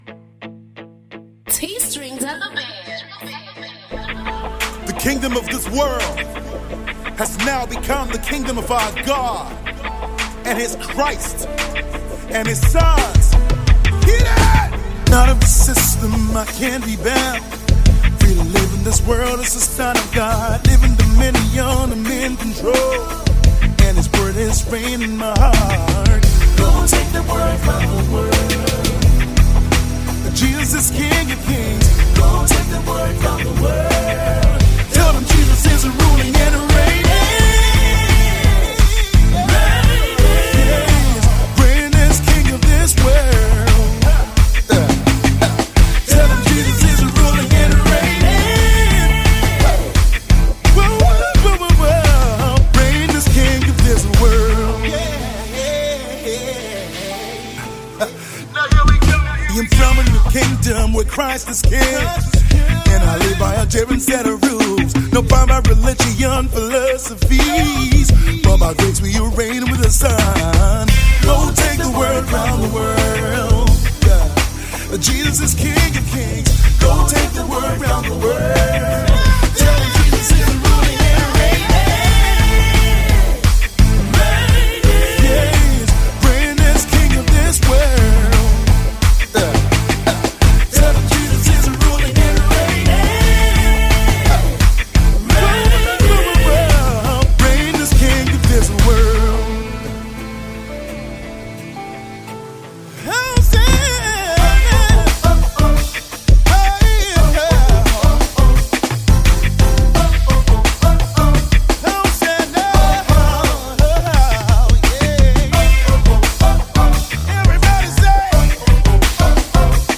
a fine fusion of Rock and Hip Hop